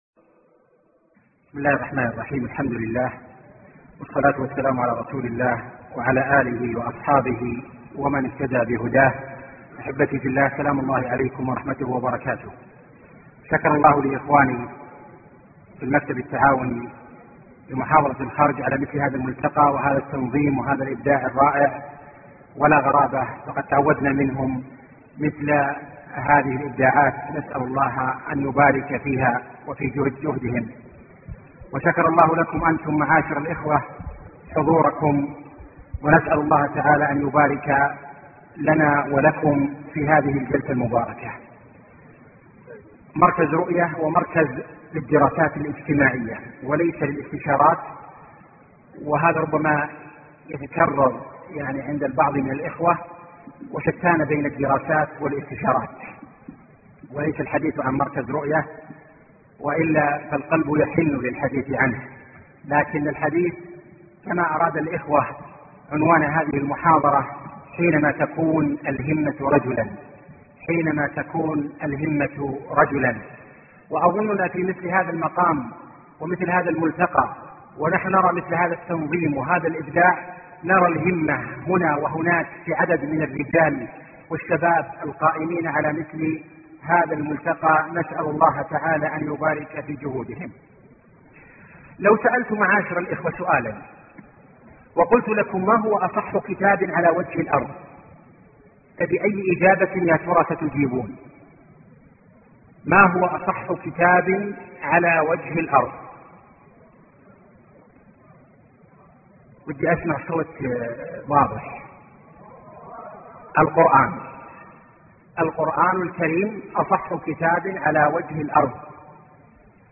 محاضراة